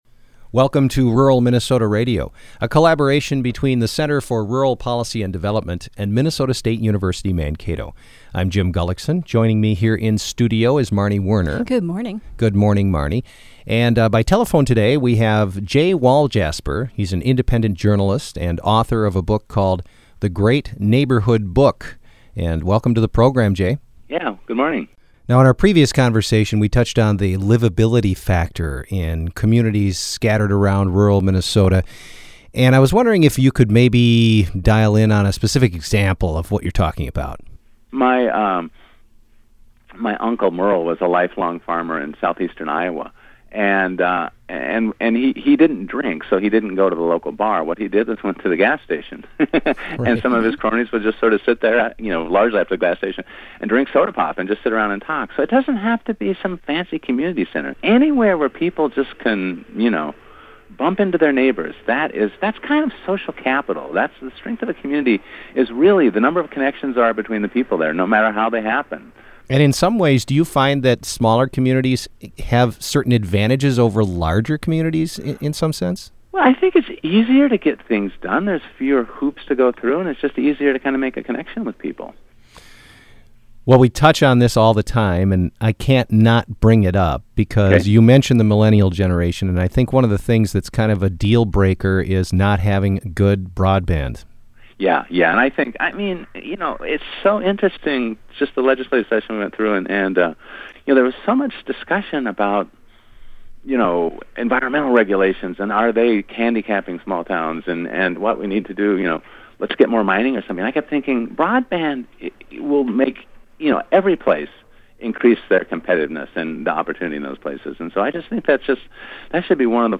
three-part interview